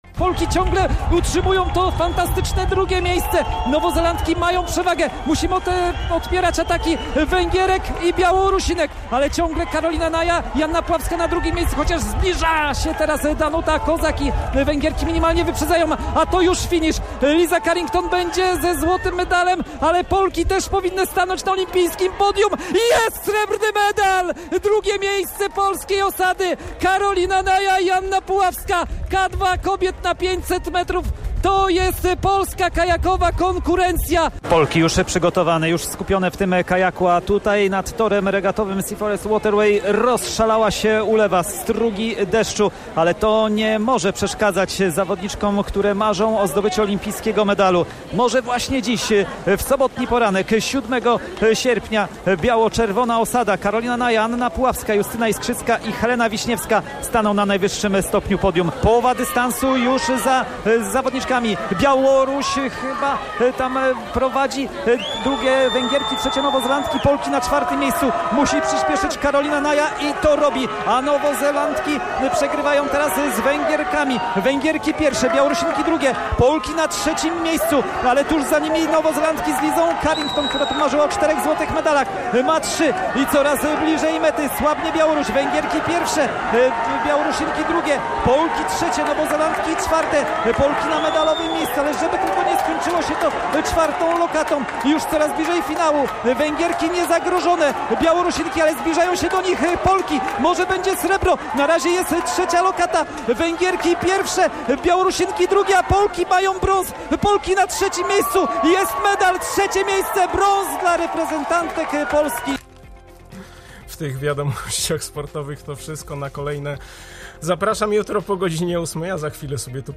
Dwukrotna medalistka olimpijska odwiedziła nasze studio w czwartek